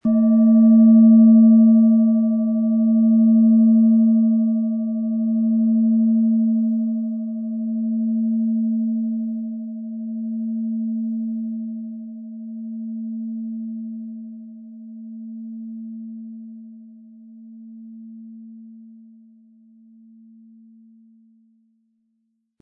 Handgearbeitete tibetische Schale mit dem Planetenton Venus.
Wie klingt diese Schale?
Im Audio-Player - Jetzt reinhören hören Sie genau den Original-Klang der angebotenen Schale. Wir haben versucht den Ton so authentisch wie machbar aufzunehmen, damit Sie gut wahrnehmen können, wie die Klangschale klingen wird.
Der Klöppel lässt die Klangschale voll und harmonisch tönen.
MaterialBronze